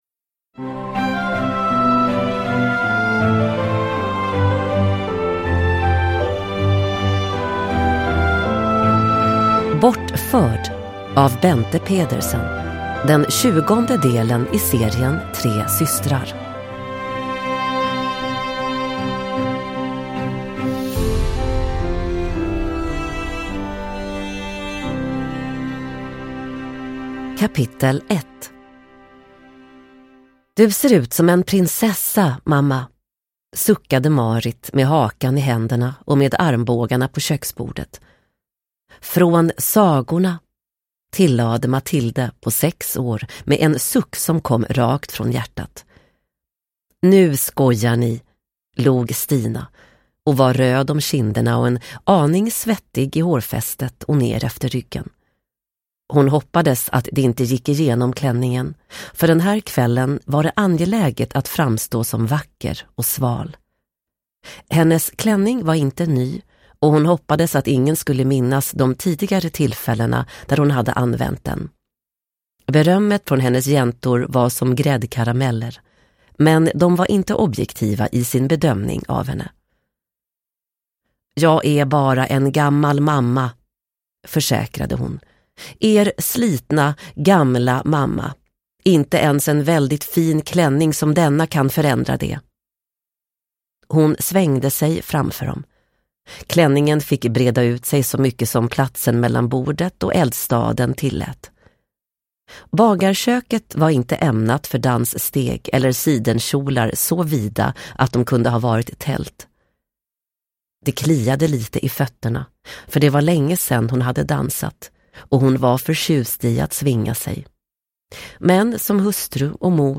Bortförd – Ljudbok – Laddas ner